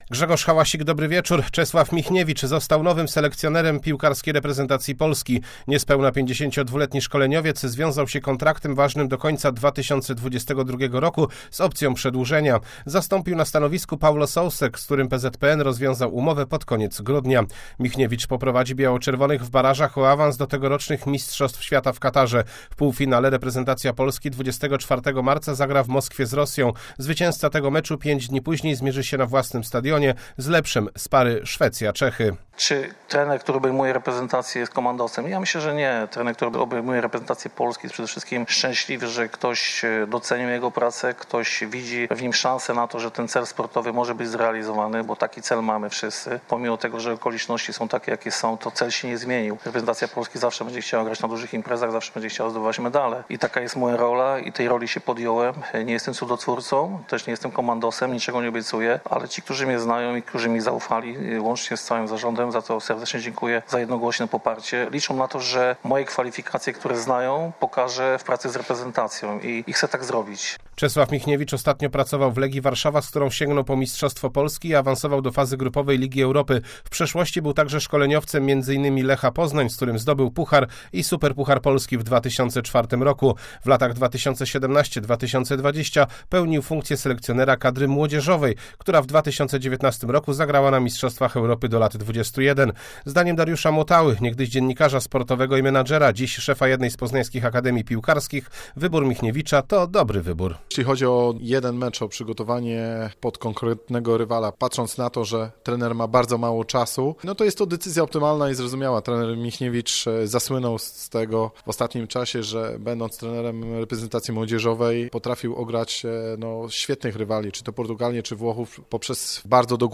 31.01.2022 SERWIS SPORTOWY GODZ. 19:05